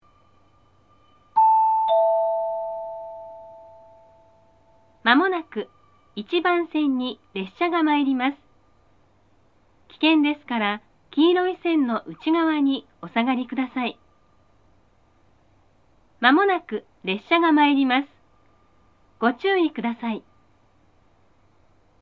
（女性）
接近放送
下り列車の接近放送です。こちらも同様。